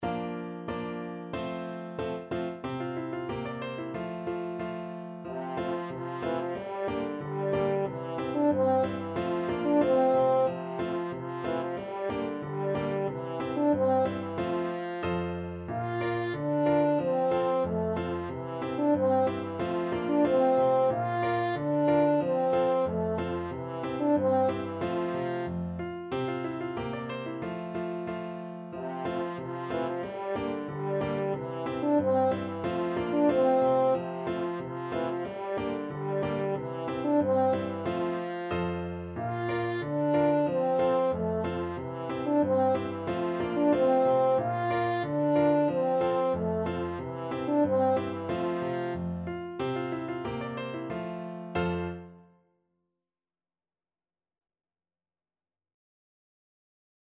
Classical Trad. Vo Luzern uf Waggis zue French Horn version
F major (Sounding Pitch) C major (French Horn in F) (View more F major Music for French Horn )
Jolly =c.92
2/2 (View more 2/2 Music)
Swiss